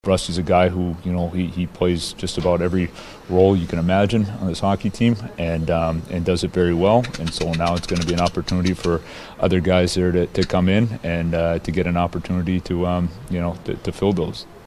Muse says his absence will open a door for some other player.